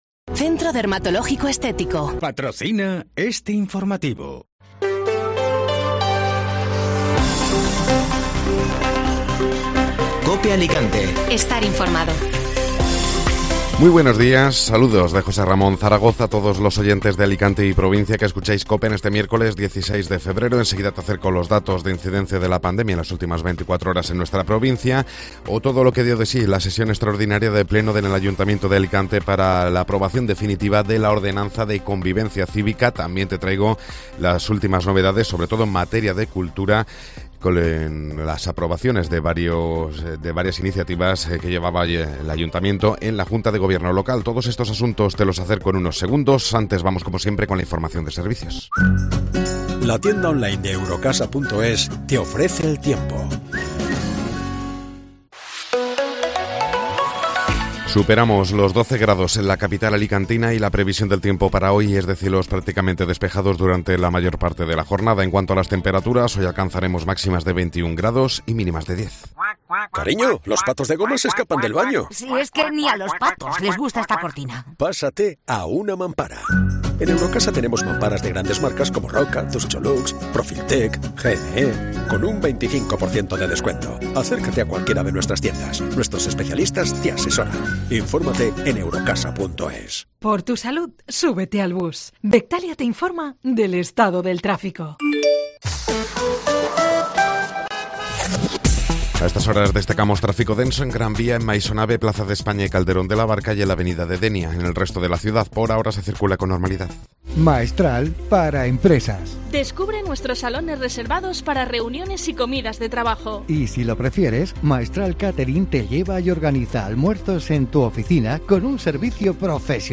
Informativo Matinal (Miércoles 16 de Febrero)